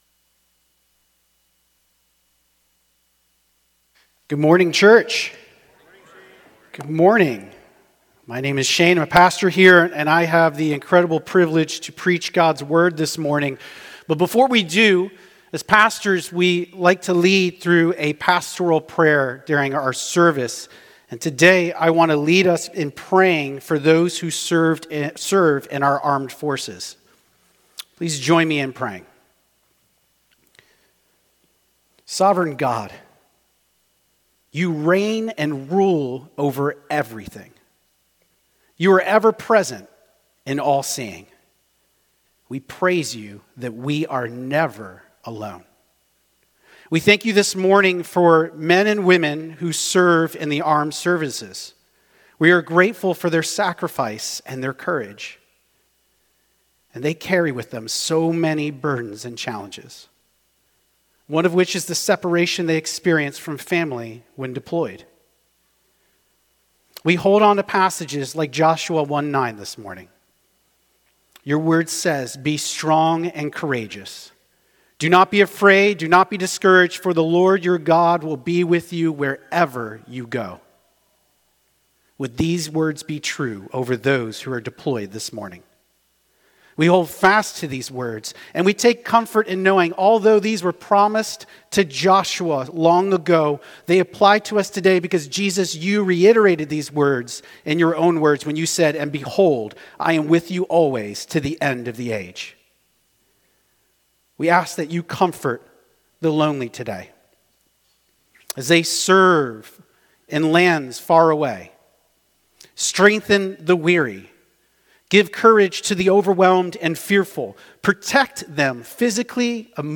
Metro Life Church Sermons